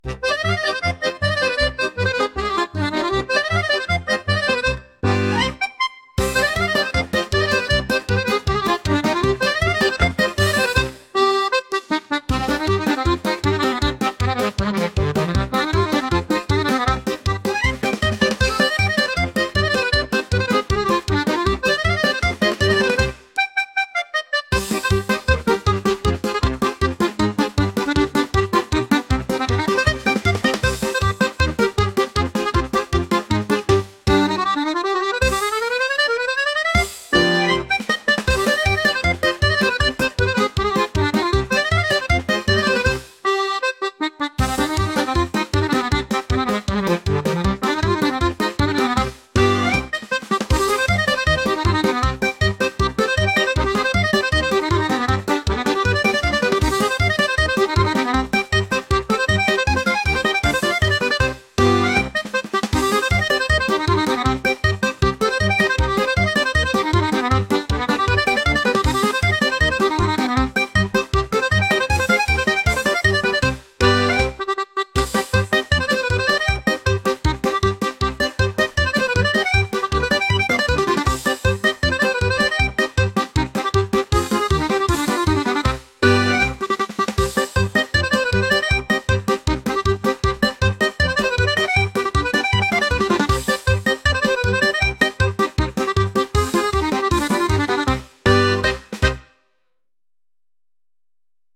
ハイテンポなアコーディオン曲です。